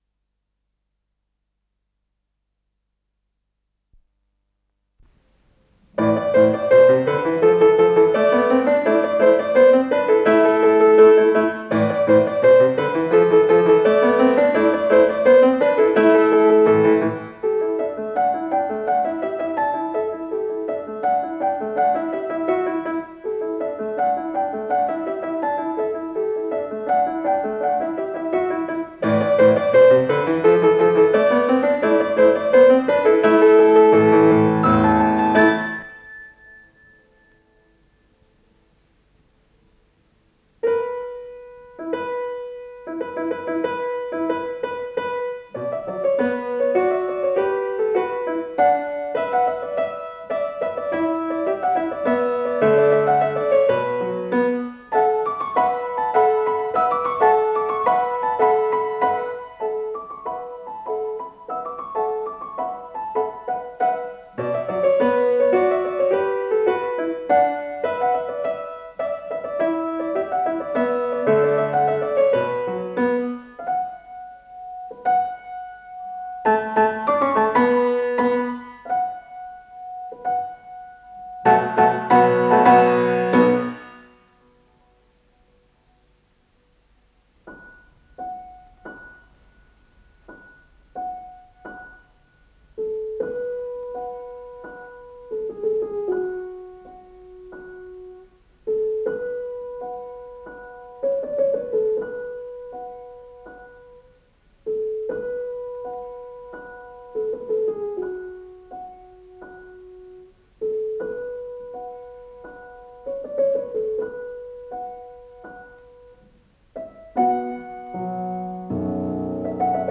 с профессиональной магнитной ленты
ля минор
соль минор
фортепиано